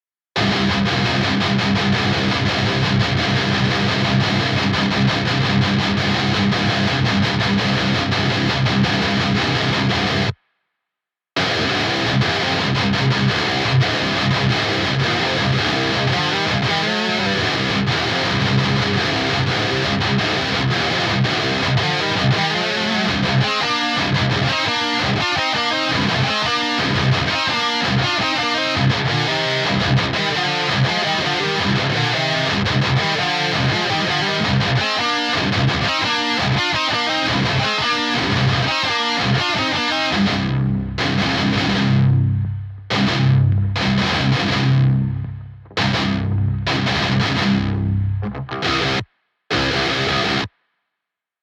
Metalcore - ���� ��� �����, ����� �������)